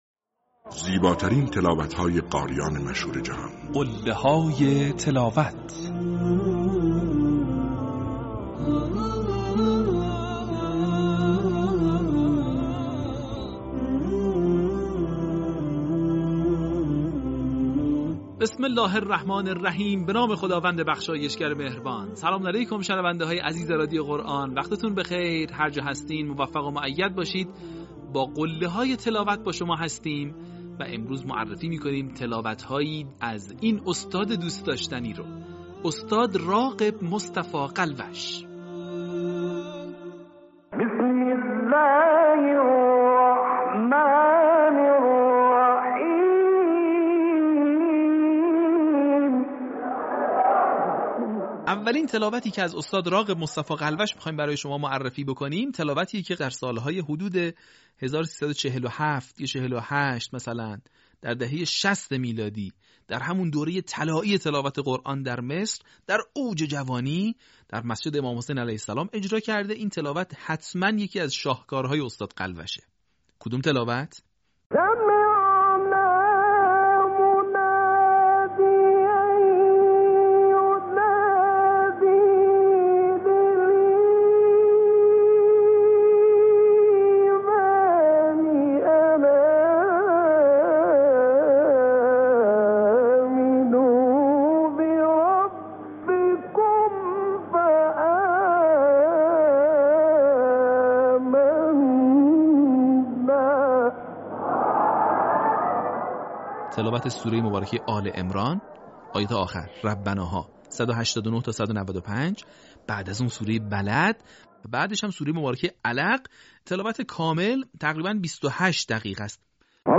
در قسمت دوم، فرازهای شنیدنی از تلاوت‌های به‌یاد ماندنی استاد راغب مصطفی غلوش را می‌شنوید.
برچسب ها: راغب مصطفی غلوش ، تلاوت ماندگار ، قله های تلاوت